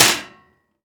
metal_impact_light_01.wav